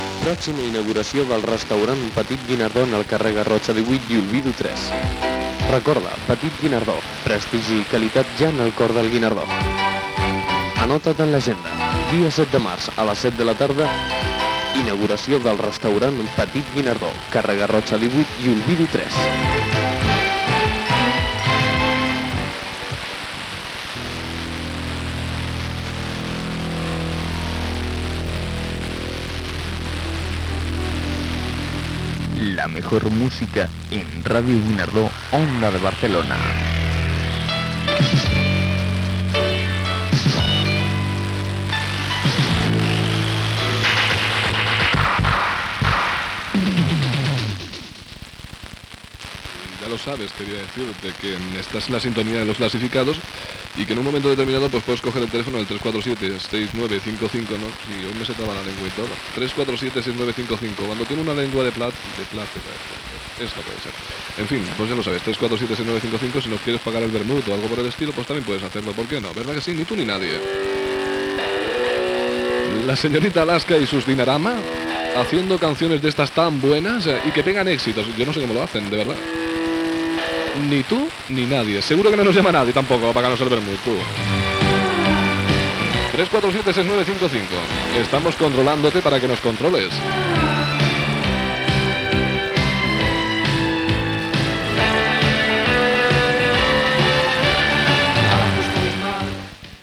6d332083a8783b115f0136f8411b169f1d297d82.mp3 Títol Ràdio Guinardó Emissora Ràdio Guinardó Titularitat Tercer sector Tercer sector Barri o districte Descripció Publicitat, Indicatiu i tema musical.